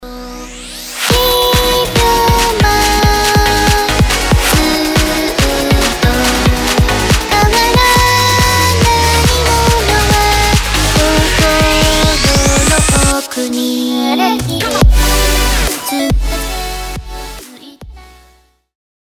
FutureBase
電波ソング盛り盛りのPOPなCDが完成